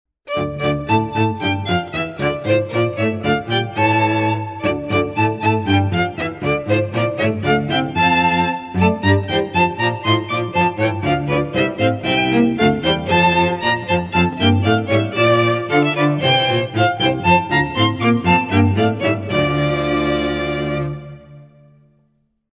The Lone Star Strings have such a beautiful sound.